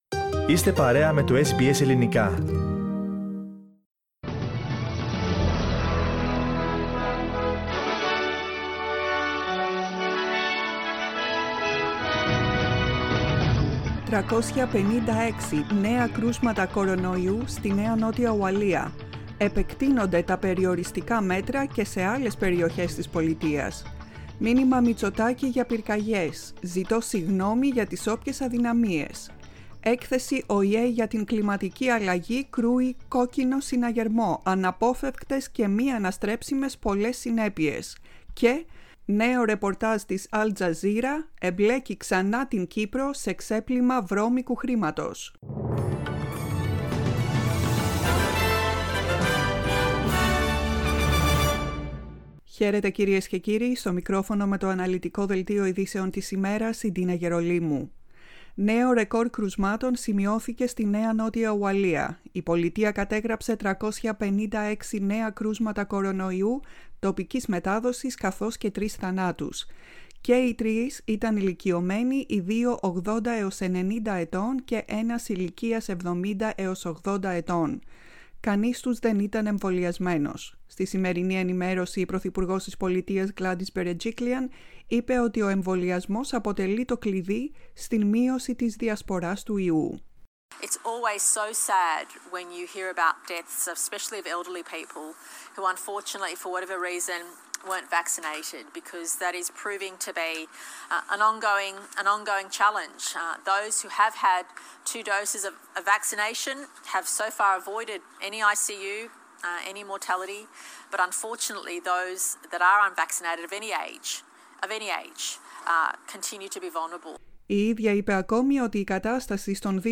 News Bulletin, 10.08.21